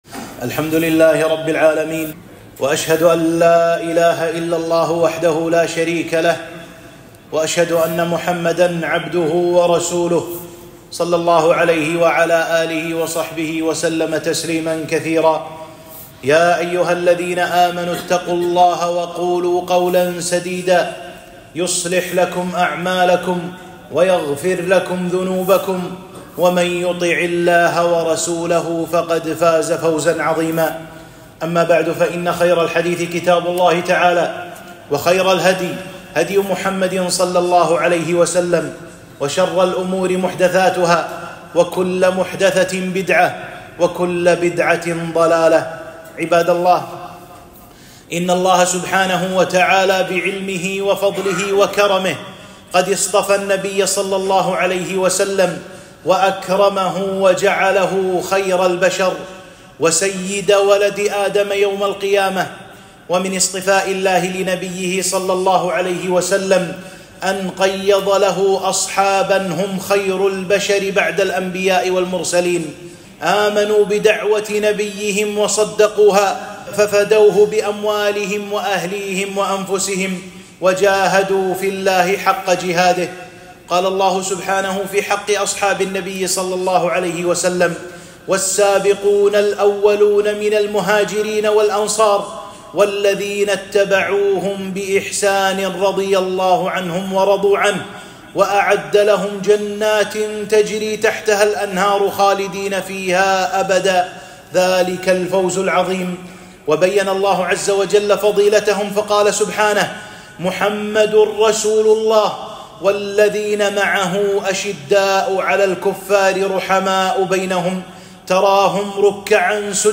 خطبة - أمير المؤمنين الفاروق عمر بن الخطاب رضي الله عنه فضائله ومكانته